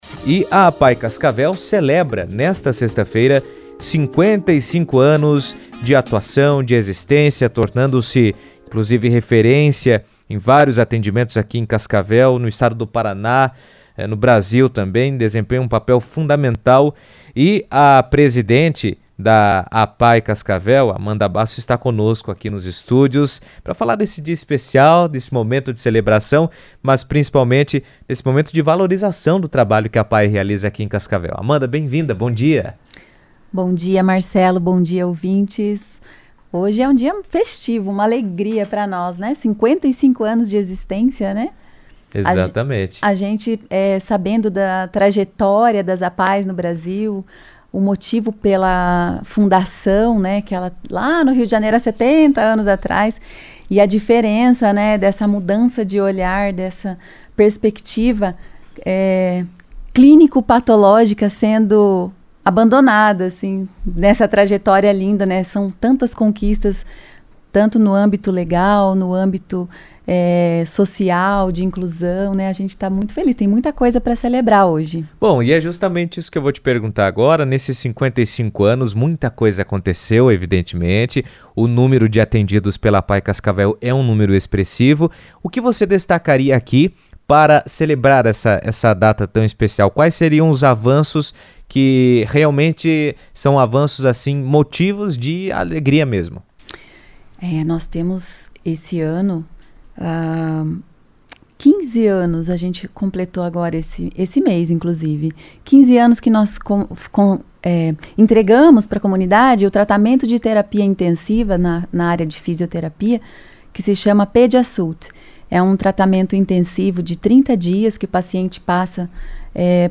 A APAE de Cascavel celebra 55 anos de atuação nesta sexta-feira, 17 de abril, consolidando-se como referência no atendimento a pessoas com deficiência intelectual e múltipla, com serviços nas áreas de educação, saúde e assistência social. Em entrevista à CBN